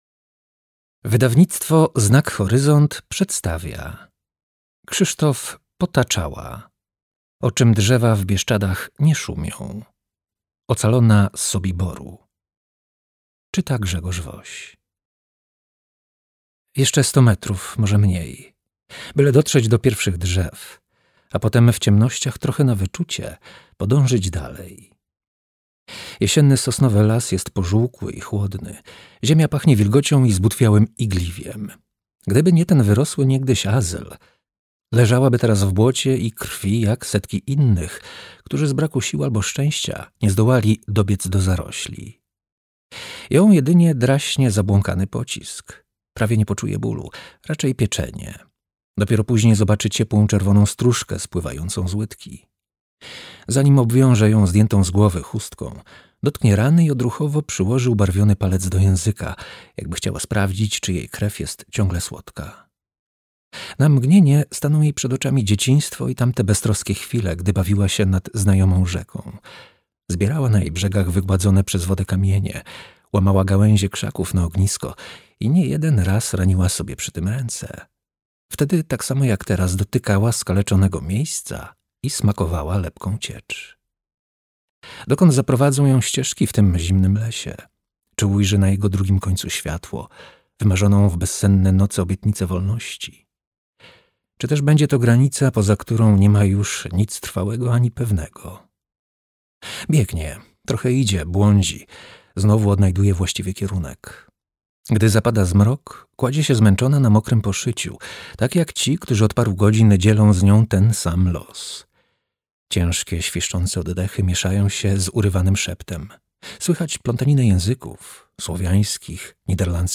O czym drzewa w Bieszczadach nie szumią. Ocalona z Sobiboru - Krzysztof Potaczała - audiobook